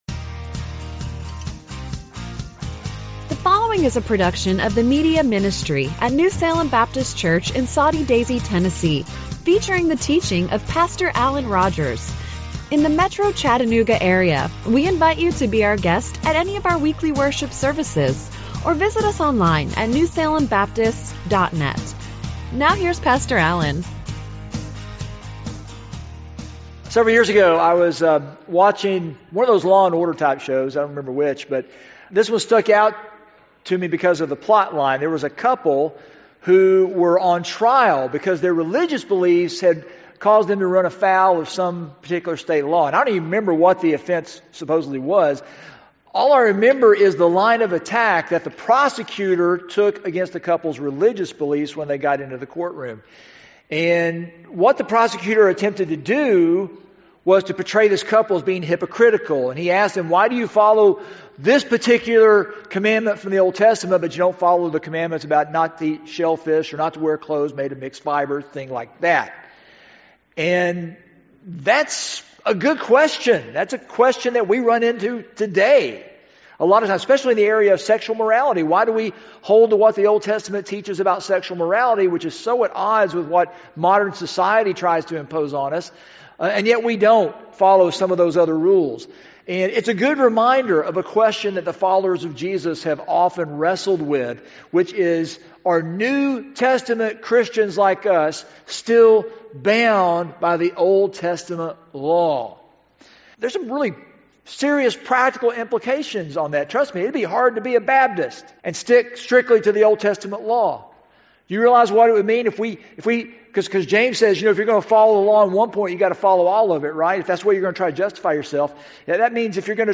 Sermons - New Salem Baptist Church of Soddy Daisy Tennessee